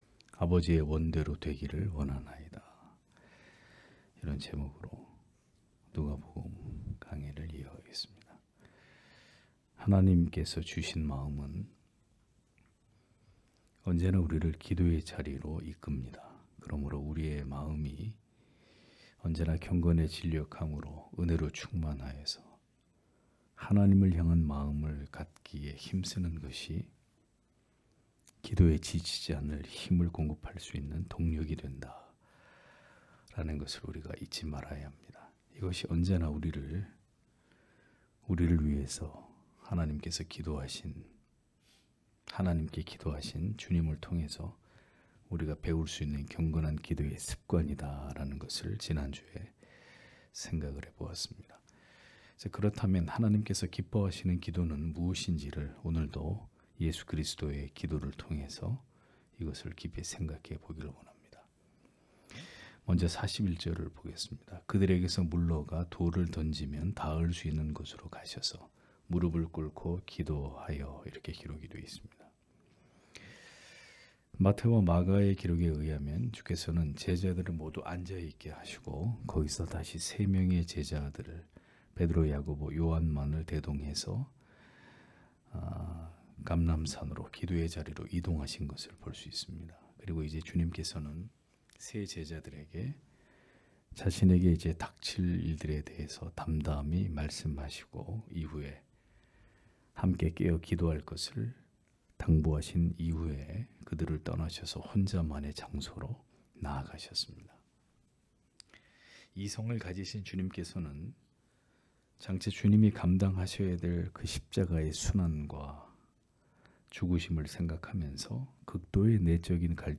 금요기도회 - [누가복음 강해 169] '아버지의 원대로 되기를 원하나이다' (눅 22장 41- 42절)